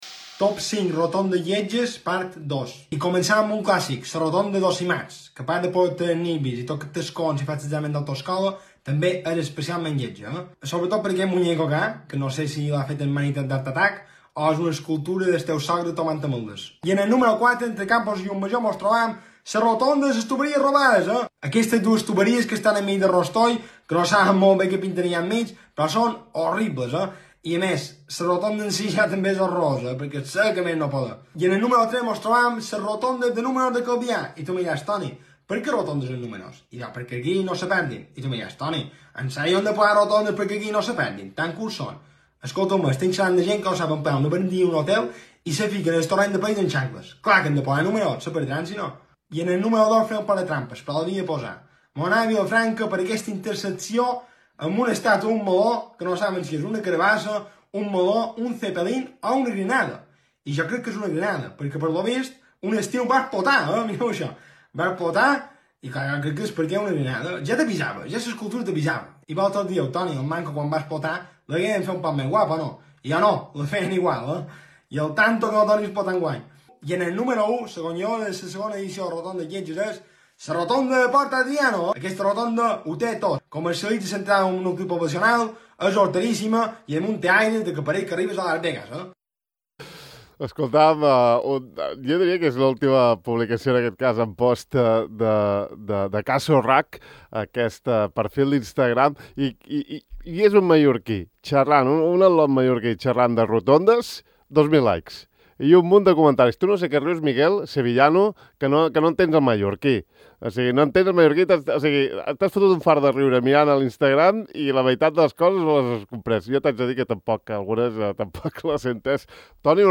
Avui hem xerrat amb ell al De far a far. Podeu escoltar l’entrevista sencera aquí: